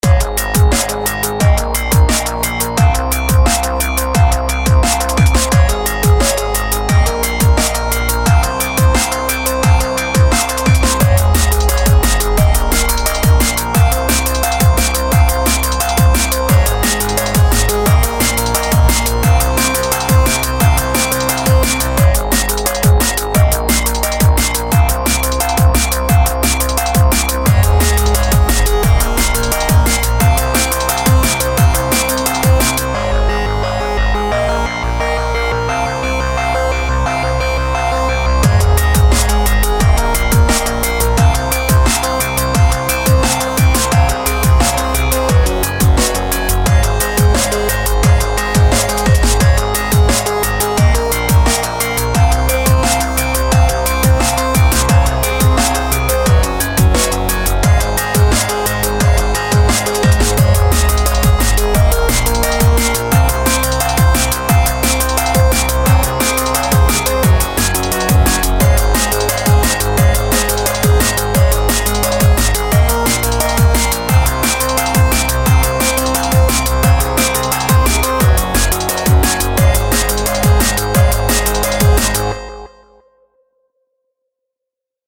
I spiked this up to 175bpm and went nuts with what I thought sounded cool..
i love that kick, nice and hard(that what she said) but you need to make the snare more prominent, make it snap more.